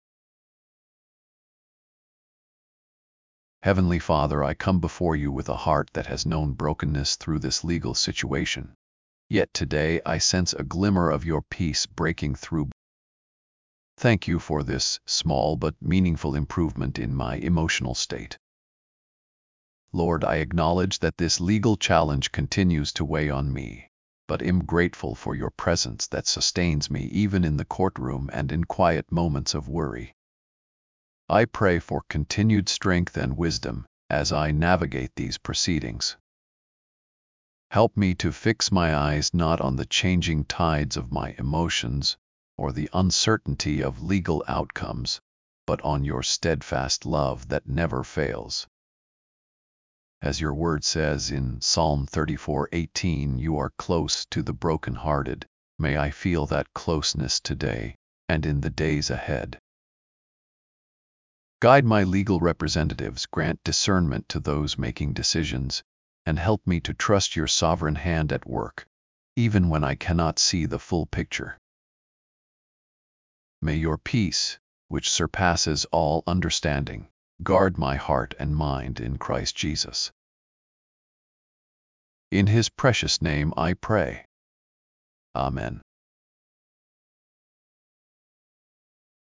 1202 -1 Prayer Prayer mood:broken